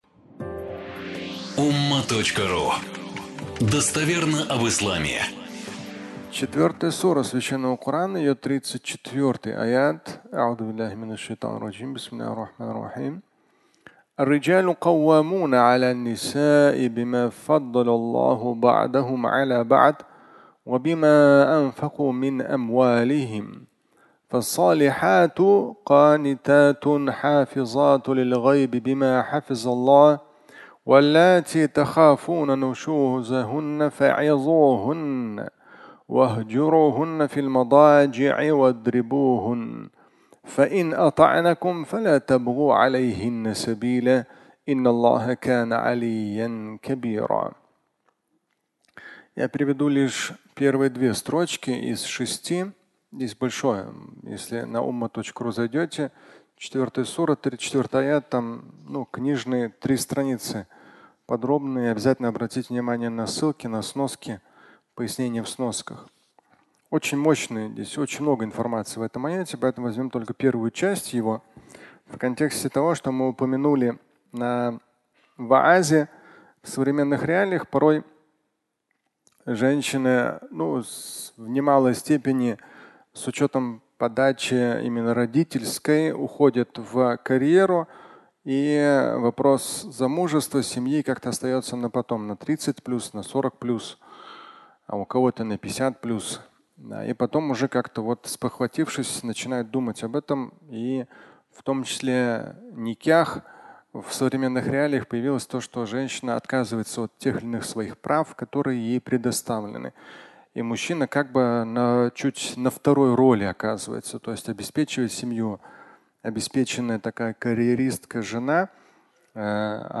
Фрагмент пятничной проповеди